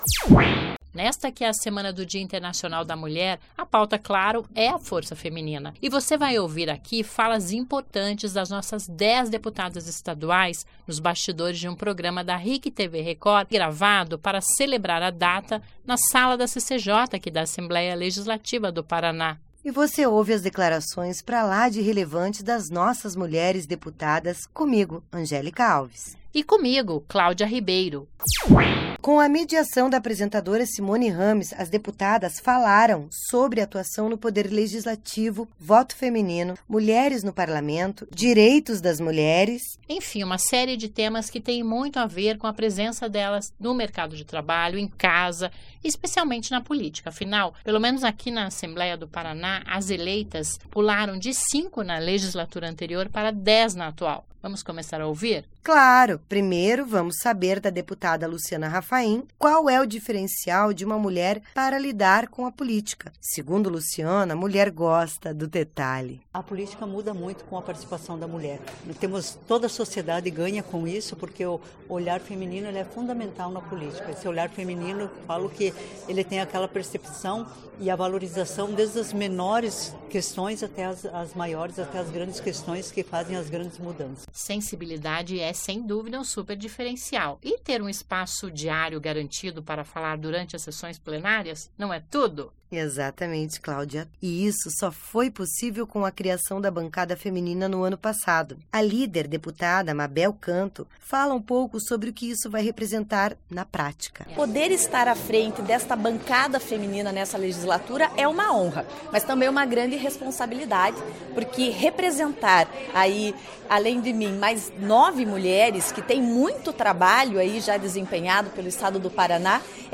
Ouça as falas femininas da Assembleia nos bastidores de um programa só com as deputadas estaduais